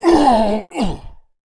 monster / barbarian_knight / dead_1.wav
dead_1.wav